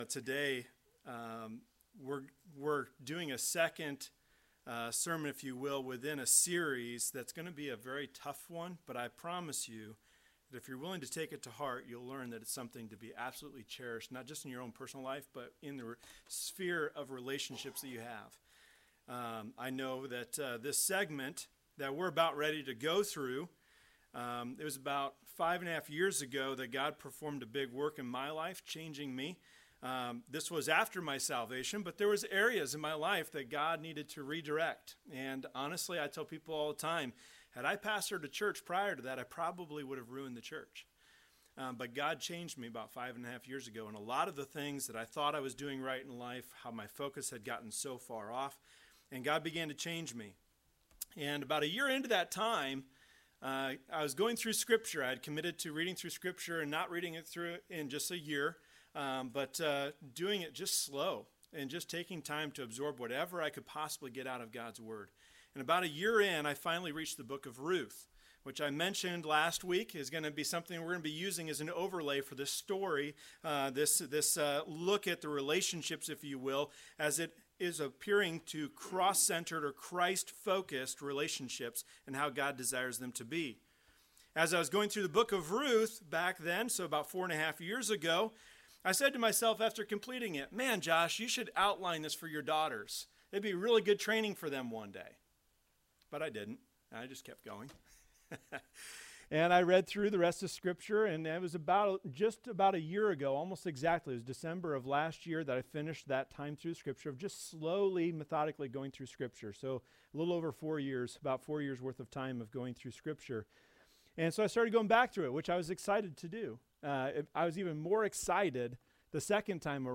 Services